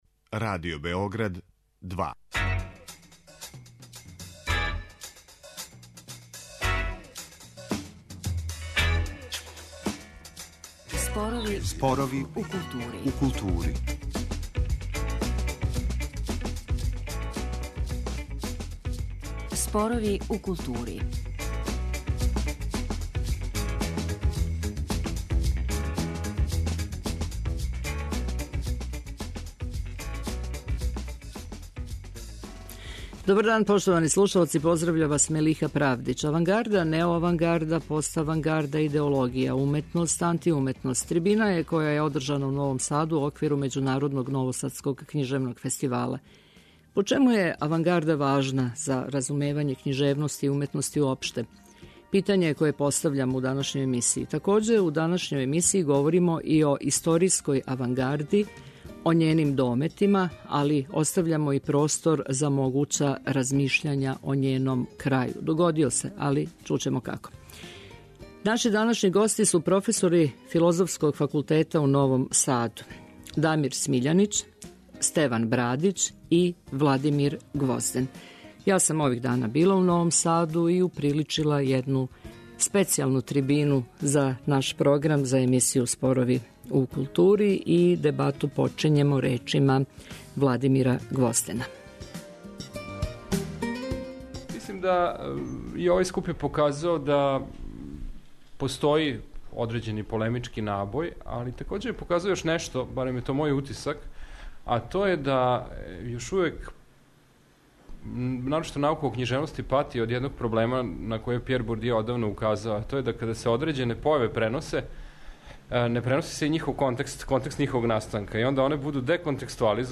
'Авангарда, неоавангарда, поставангарда, идеологија, уметност, антиуметност' трибина је која је одржана у Новом Саду у оквиру Међународног новосадског књижевног фестивала.